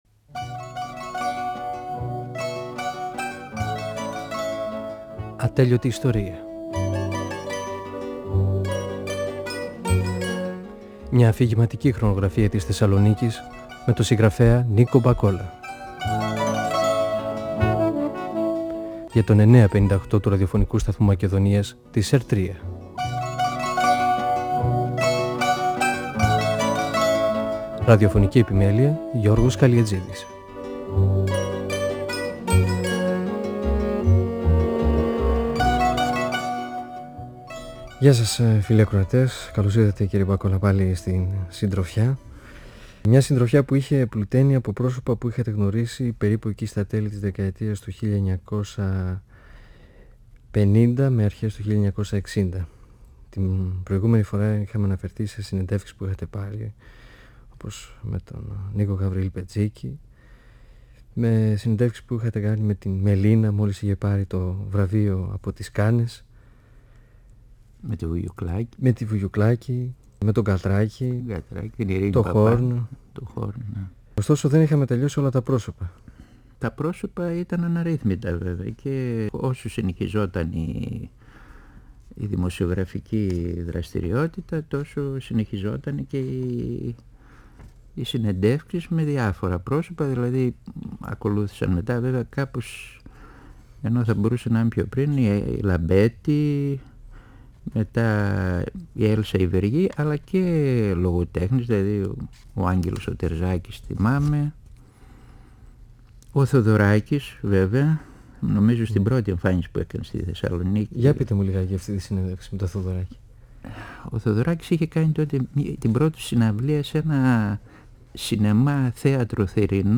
Η συνομιλία-συνέντευξη